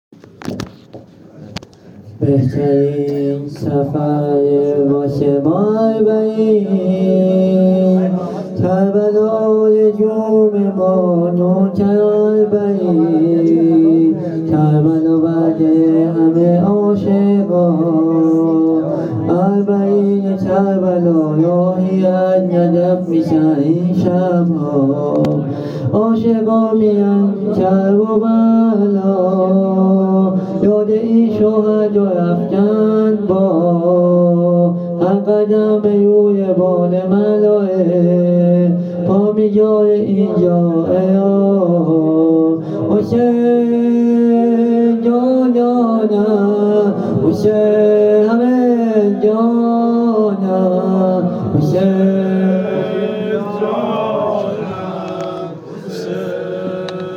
هیئت بیت العباس تهران